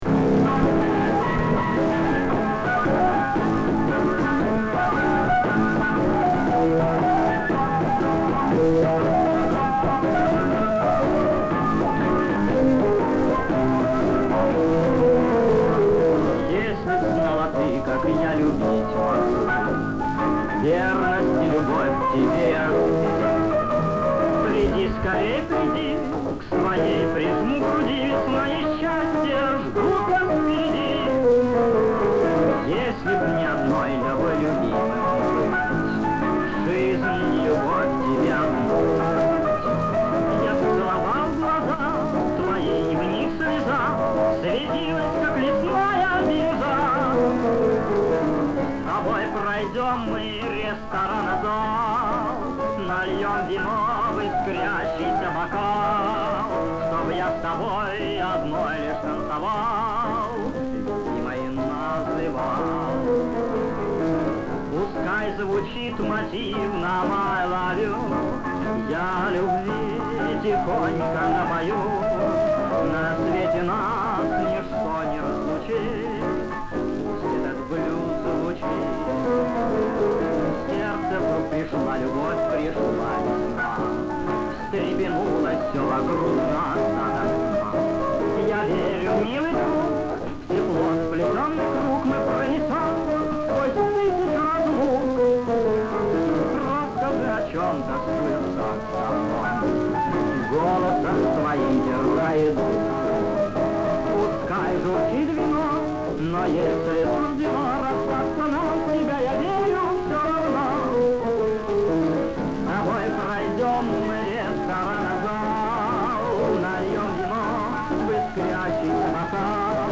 Ниже оцифровка именно этой упомянутой здесь пластинки "на рёбрах", сохранившейся с 50-х годов.